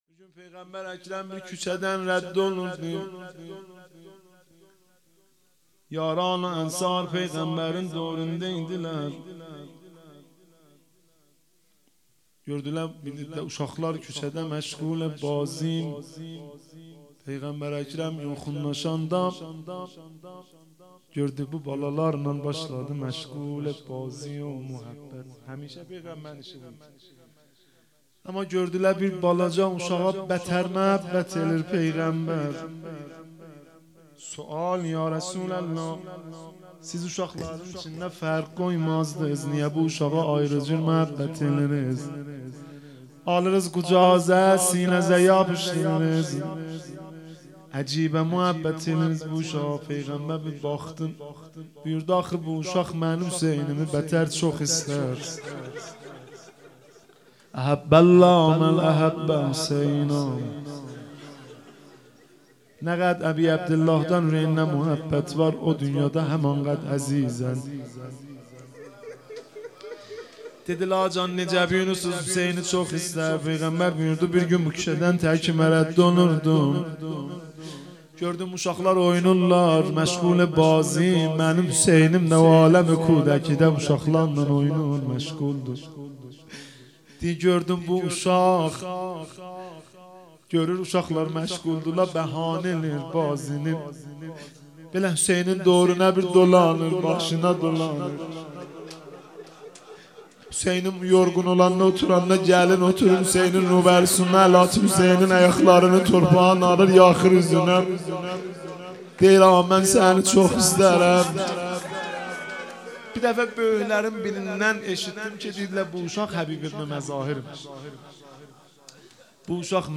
روضه ترکی - مهدی رسولی با ترافیک رایگان
روضه ترکی